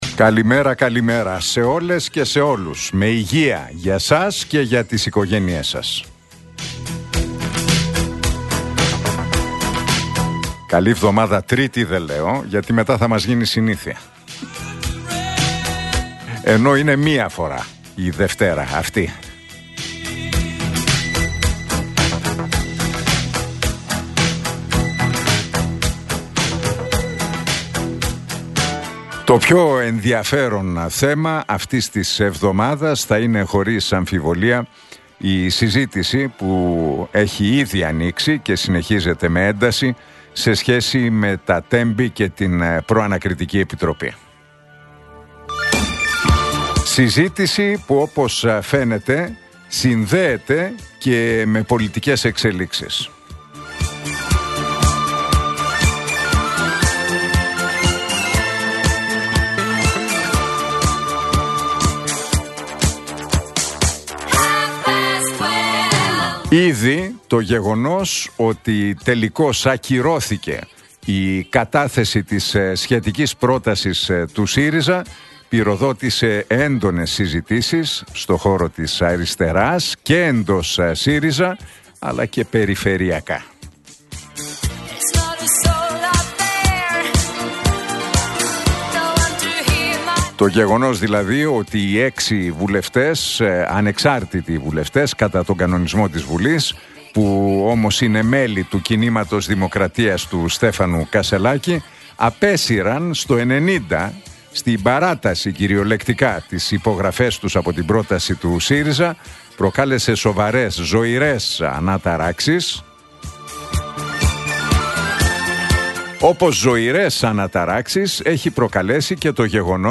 Ακούστε το σχόλιο του Νίκου Χατζηνικολάου στον ραδιοφωνικό σταθμό Realfm 97,8, την Τρίτη 10 Ιουνίου 2025.